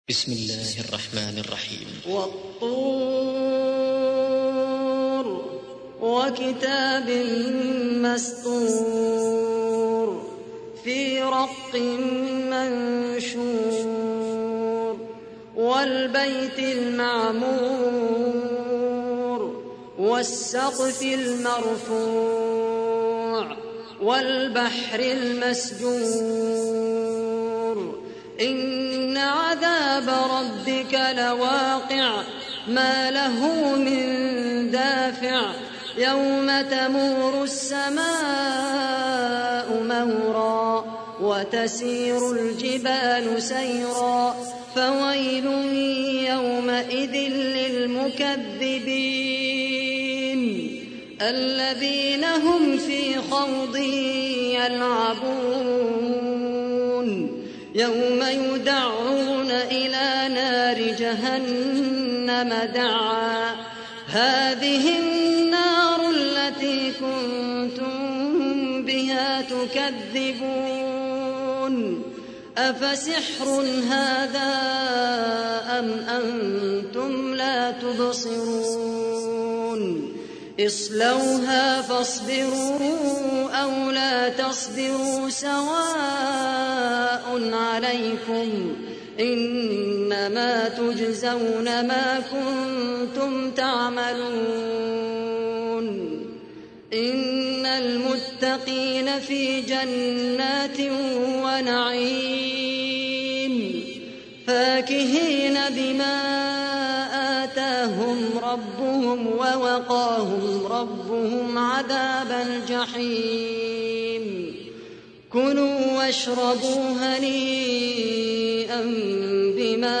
تحميل : 52. سورة الطور / القارئ خالد القحطاني / القرآن الكريم / موقع يا حسين